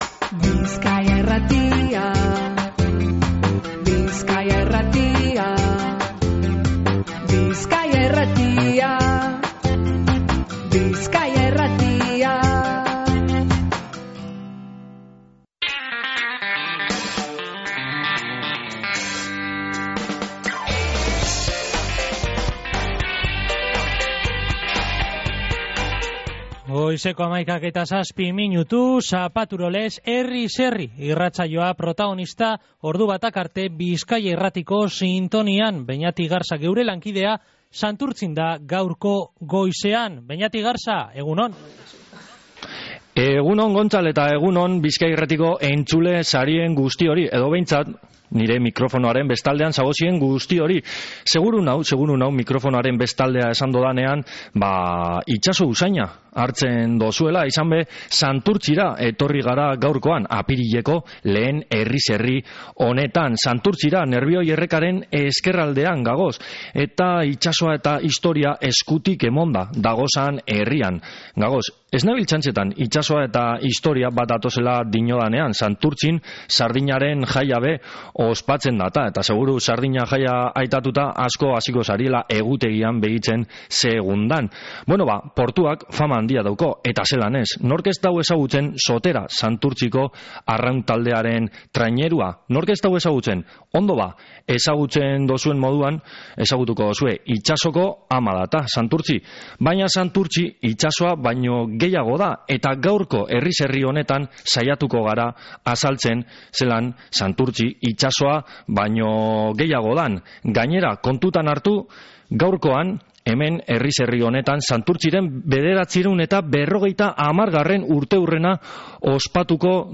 Santurtziren 950. urteurrena ospatu dogu Herriz Herri irratsaioa, inoiz izan dan jendetsuena